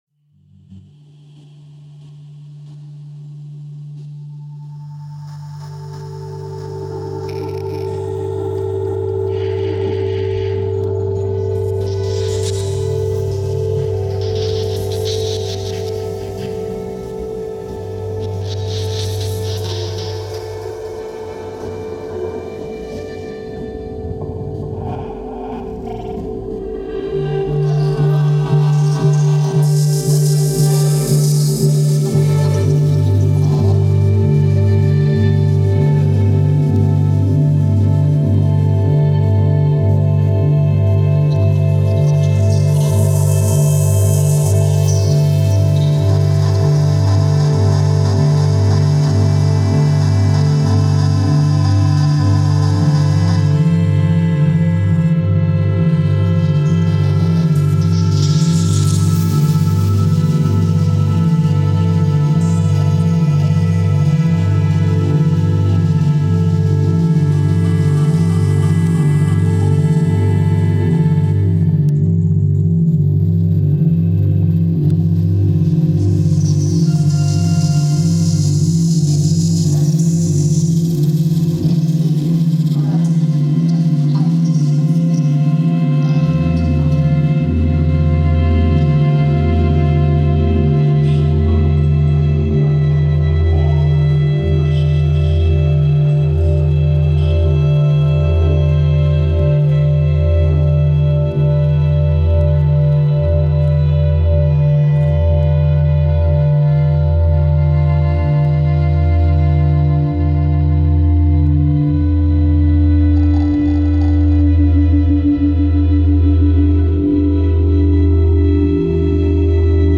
radio sound piece